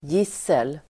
Ladda ner uttalet
Uttal: [j'is:el]